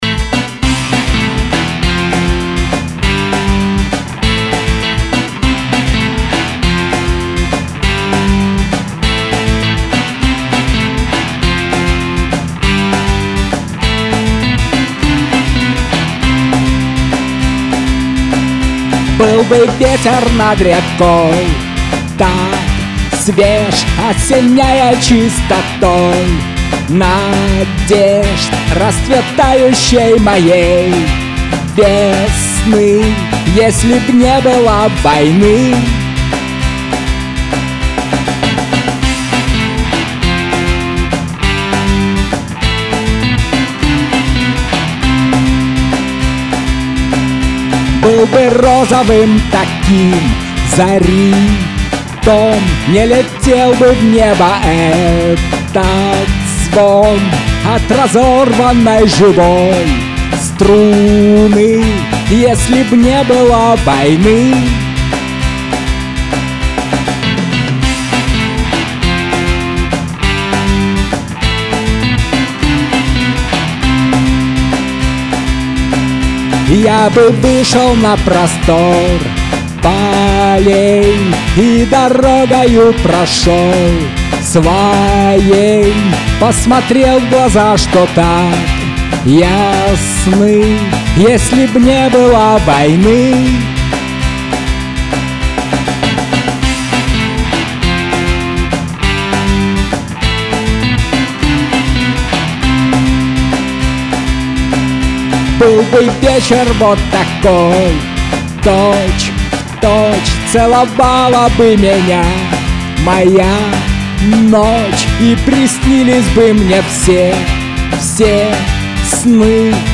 (2018, Рок)